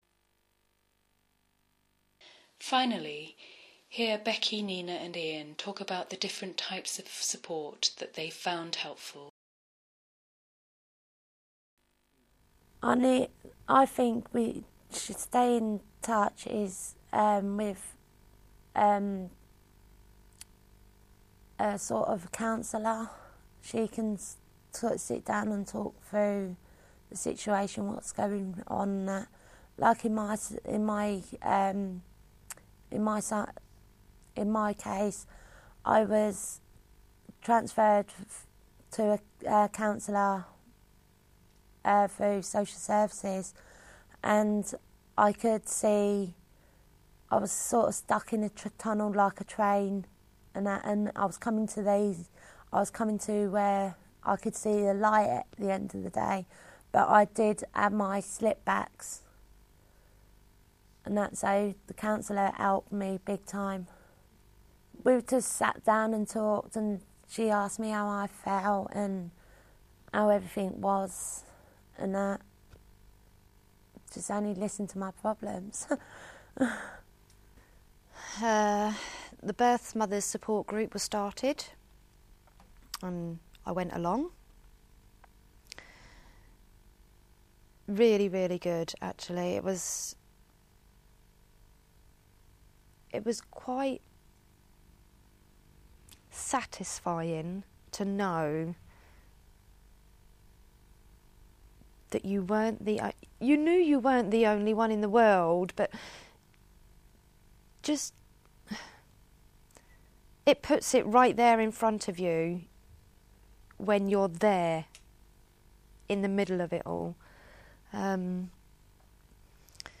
In these clips, the birth parents talk about their experiences of having a child placed for adoption.